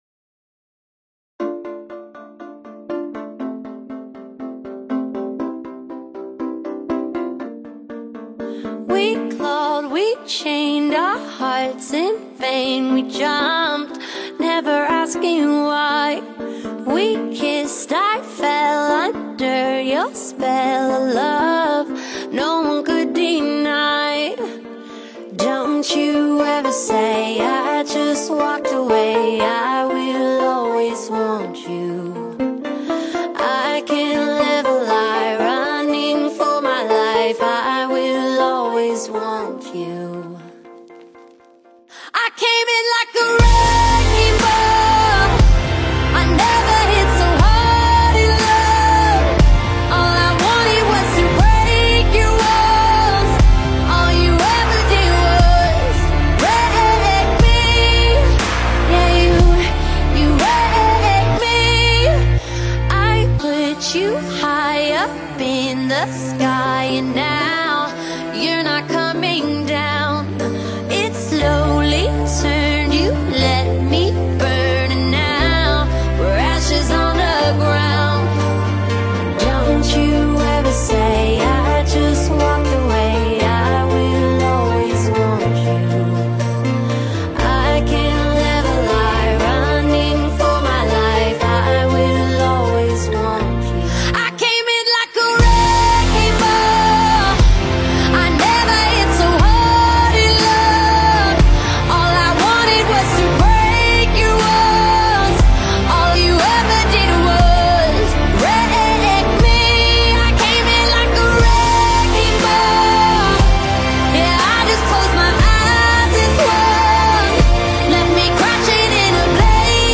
pop songs